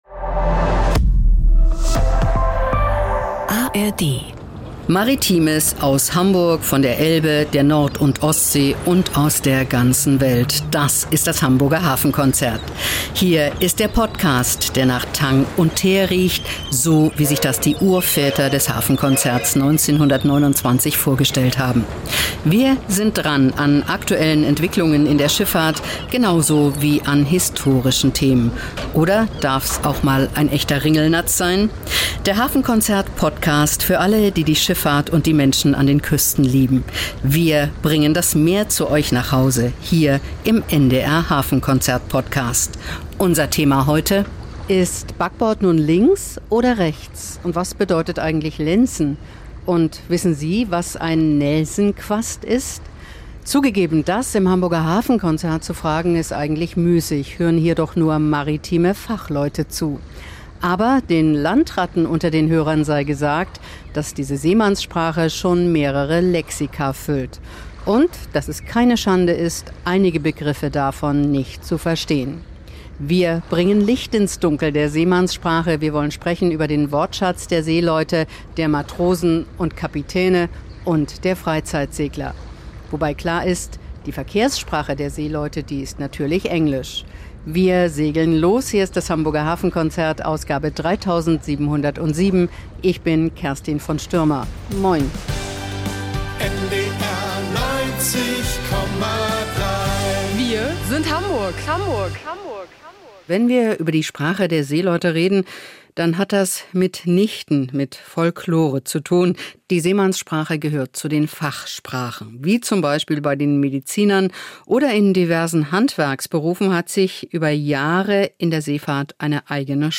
Wir sprechen mit einem Germanisten, einem Marinehistoriker, mit Segellehrerinnen und Kreuzfahrt-Experten – und hören, wieviel Humor in dieser maritimen Sprachwelt steckt.